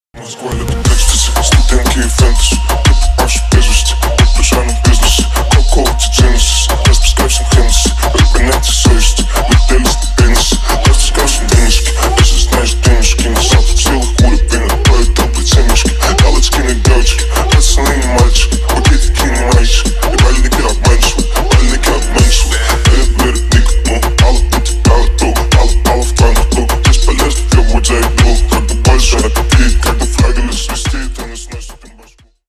Ремикс # Рэп и Хип Хоп
громкие